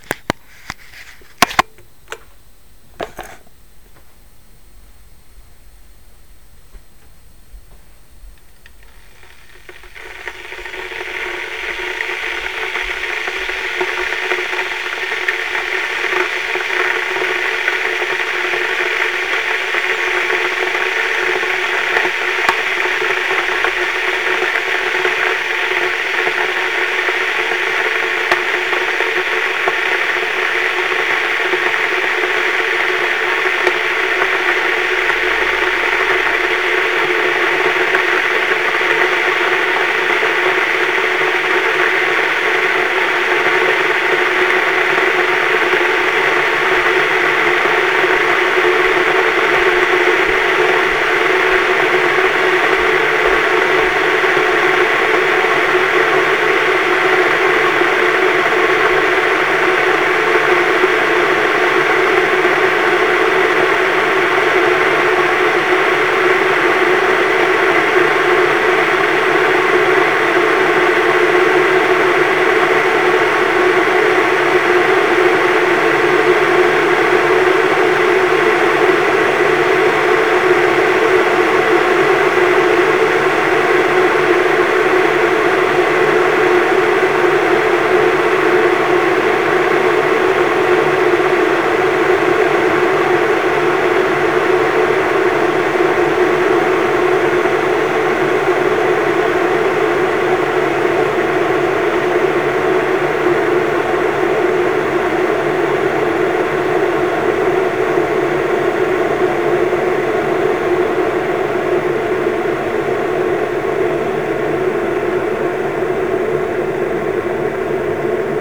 You can hear some music as the kettle boils up.
In this example the music signal is fed from the Hi-fi to a transmitter and located near the kettle boiling.
Hi-Fi connected to transmitter with kettle boiling
Hi-Fi-connected-to-transmitter-with-kettle-boiling.mp3